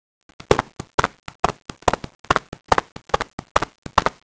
horse.wav